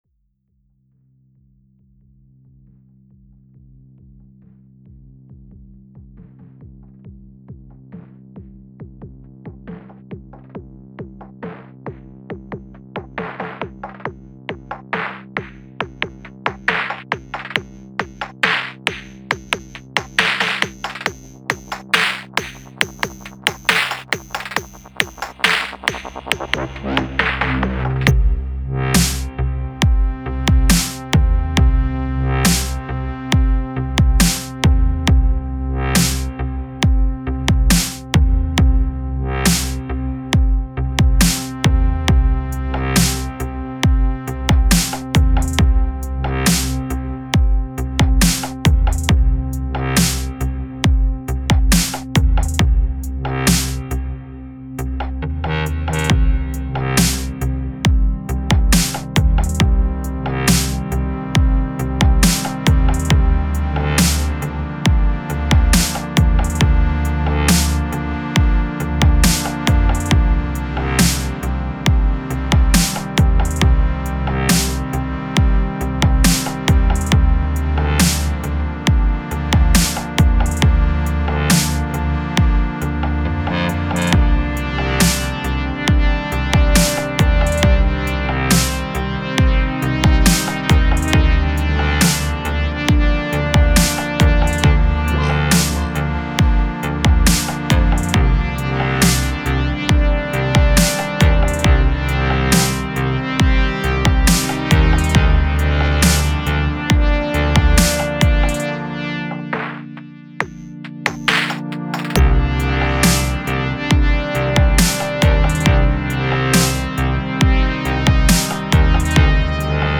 Etwas Düsterer Dubstep - Ähnlicher Track
Es wurden keine Patches oä. benutzt, Jeder Sound wurde selbst geschraubt und ein Teil der Sounds wurde dann als Sample in den Grain Sythesizer gepackt.